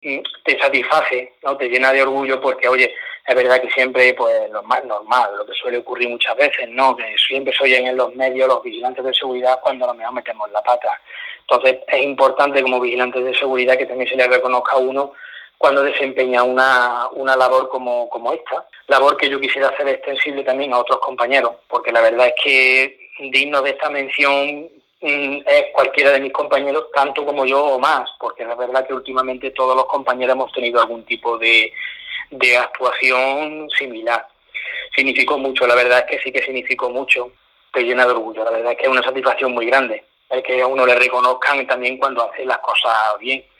con emoción formato MP3 audio(0,83 MB).